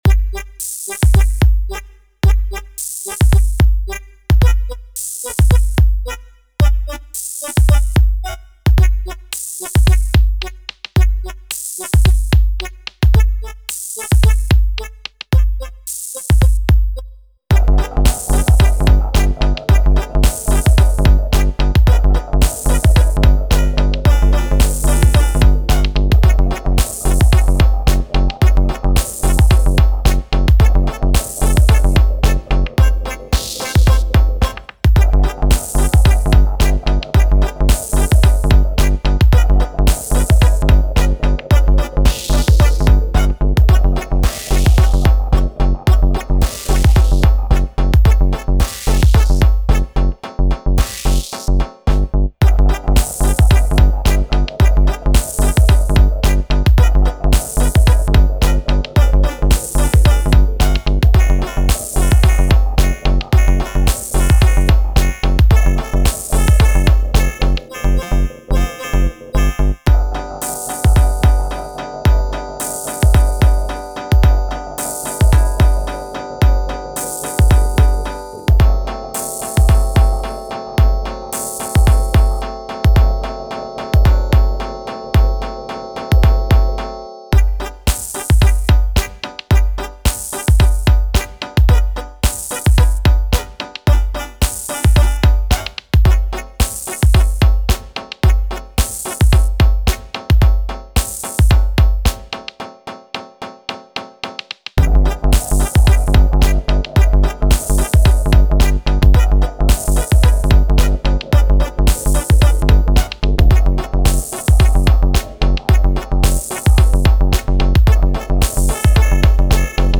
digitone+system1m
Unsettling and lovely, great combo.